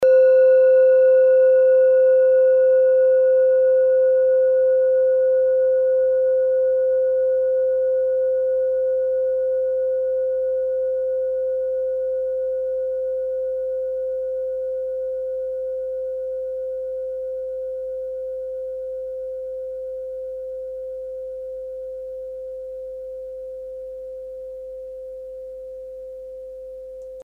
Kleine Klangschale Nr.4
Klangschale-Durchmesser: 11,3cm
Diese Klangschale ist eine Handarbeit aus Bengalen. Sie ist neu und ist gezielt nach altem 7-Metalle-Rezept in Handarbeit gezogen und gehämmert worden.
kleine-klangschale-4.mp3